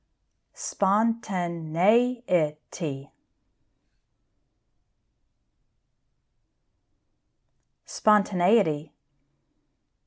I’ll say each word twice – once a little more slowly with some separation between the syllables, and then once at normal speed, and I’d like you to repeat after me both times.
e-lec-TRI-ci-ty: electricity
pe-di-a-TRI-cian: pediatrician
un-be-LIE-va-ble: unbelievable